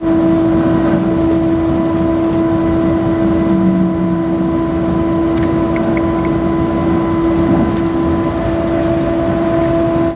Audio cabina 500